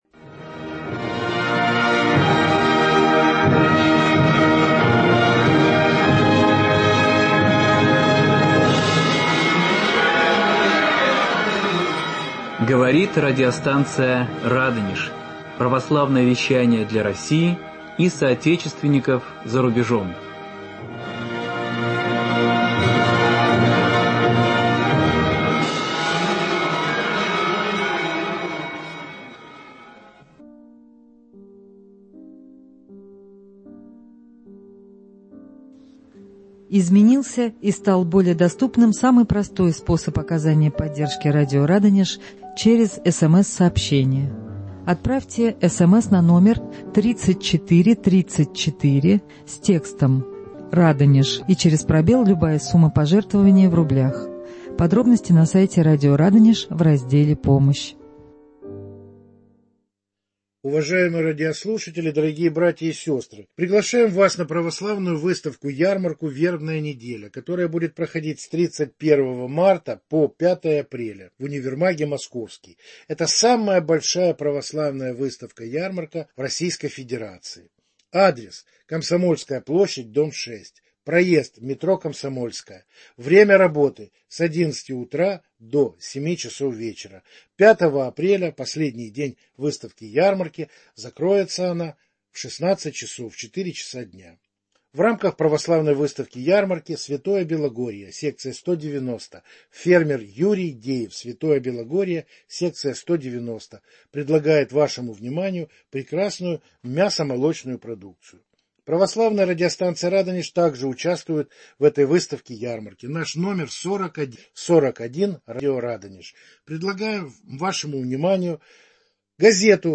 Можно ли у Бога просить что-то конкретное для себя и близких, или надо руководствоваться тем, что Он лучше знает что нам полезно?.. В прямом эфире на вопросы слушателей отвечает